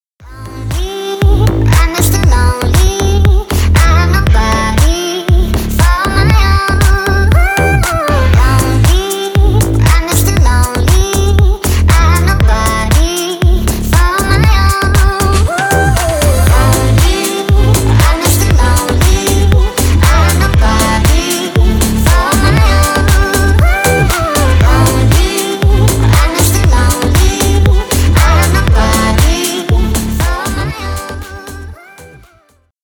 Поп Музыка # Танцевальные